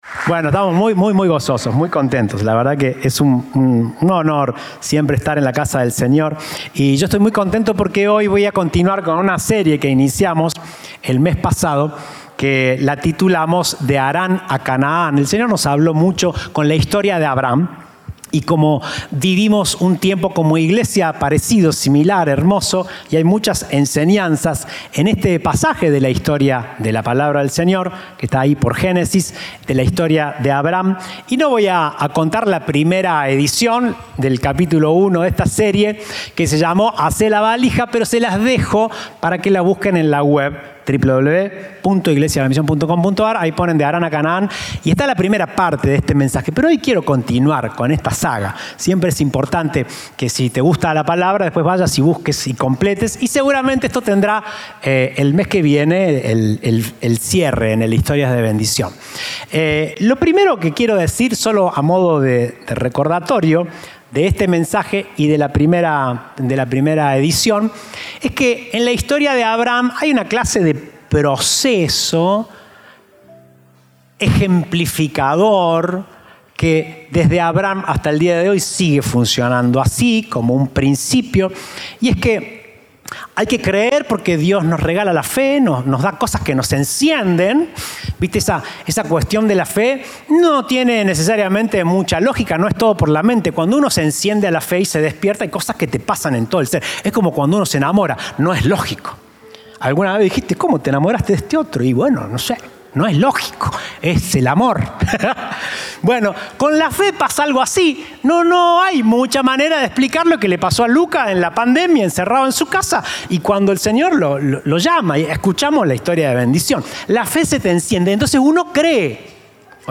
Presentamos esta Serie de mensajes titulada “De Harán a Canaán”.